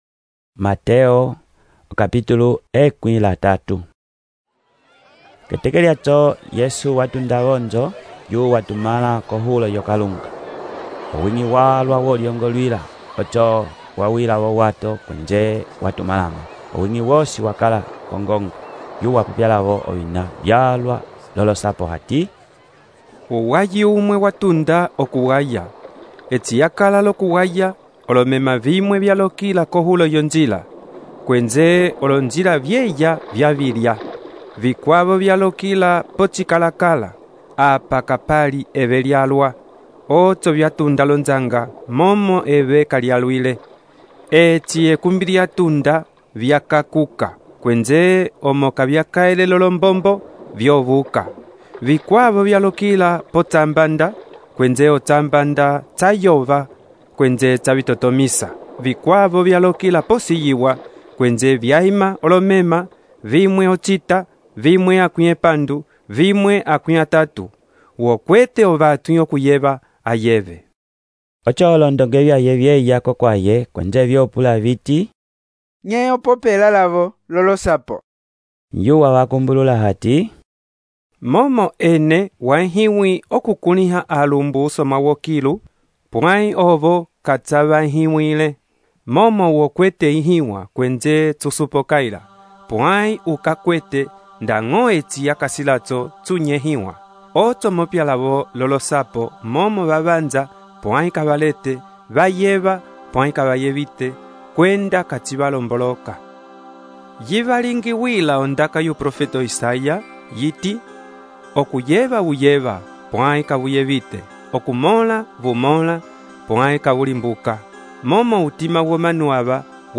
texto e narração , Mateus, capítulo 13